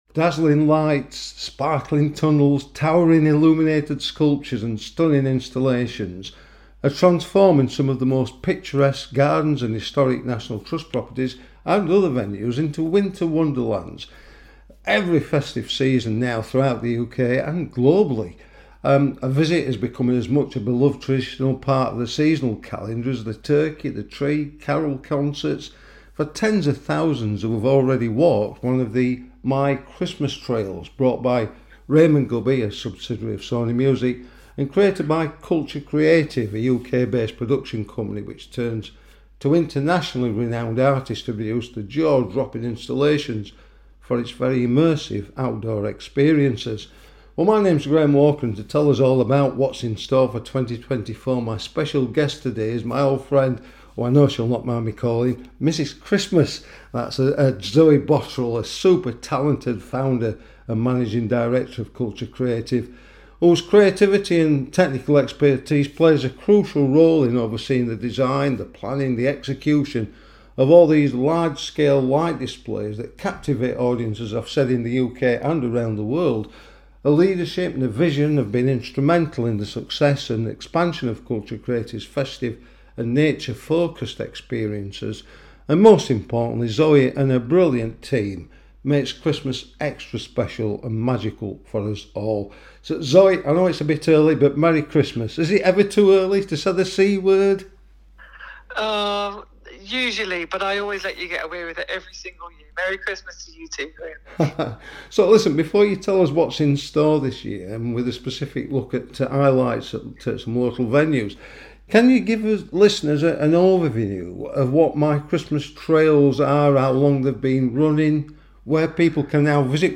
The Big Interviews